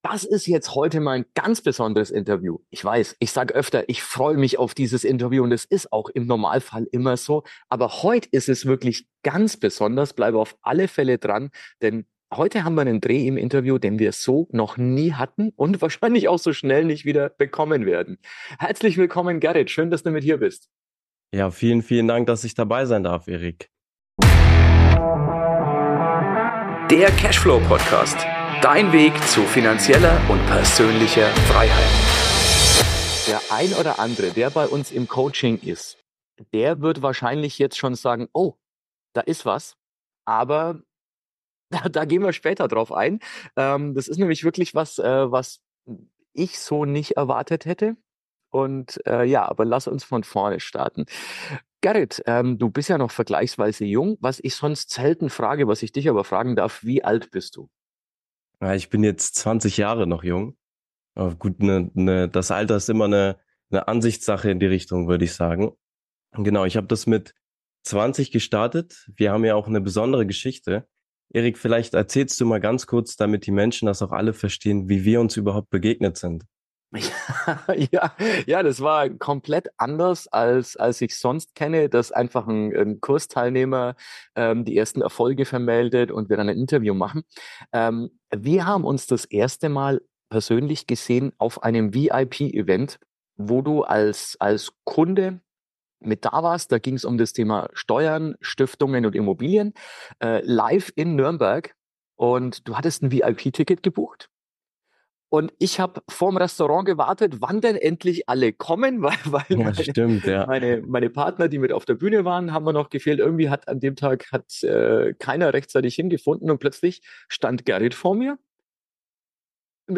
Dabei hat dieses Interview eine Wendung, die wir so noch nie hatten.